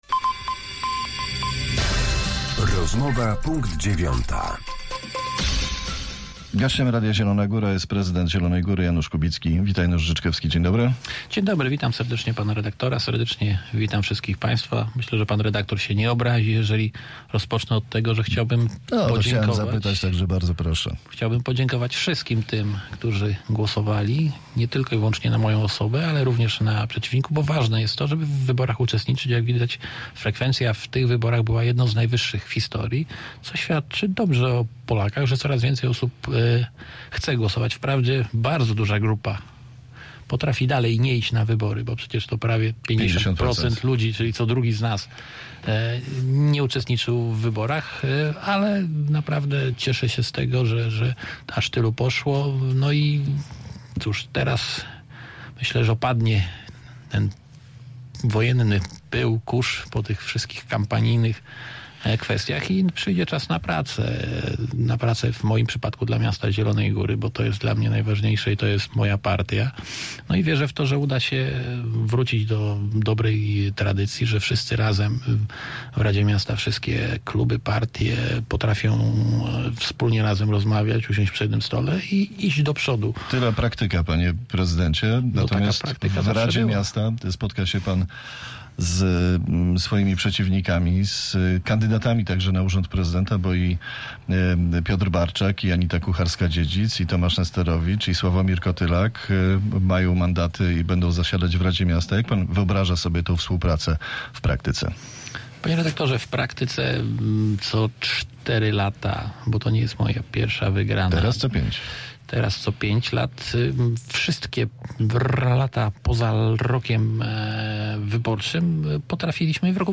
Z prezydentem miasta Zielona Góra rozmawiał